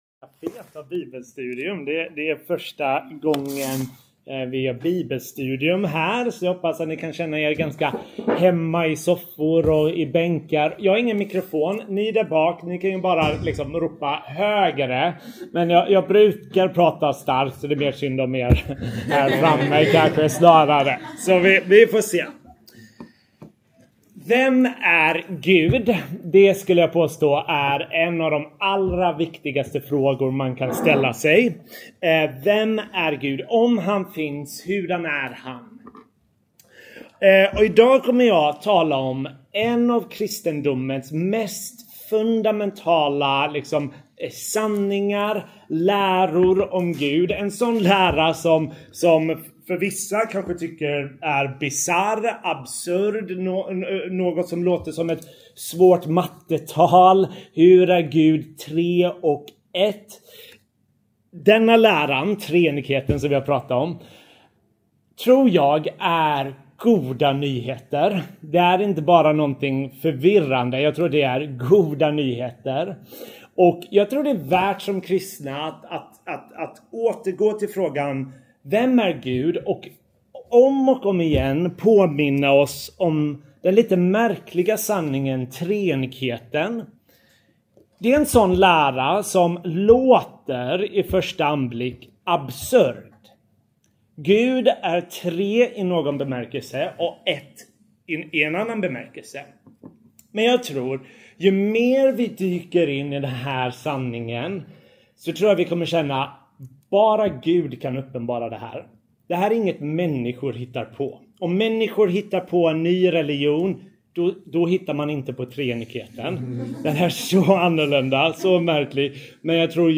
OBS – några minuter saknas i mitten av inspelningen – OBS